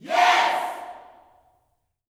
YES  04.wav